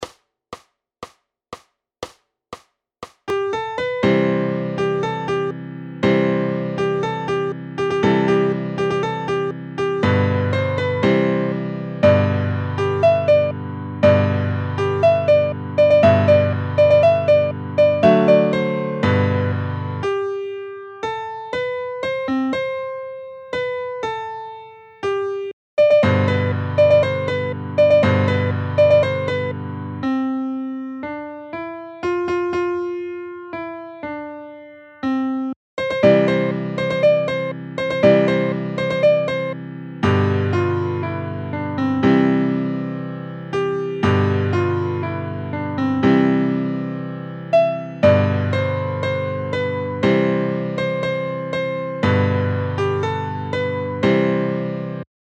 Noty na snadný sólo klavír & AUDIO.
Aranžmá Noty na snadný sólo klavír
Hudební žánr Klasický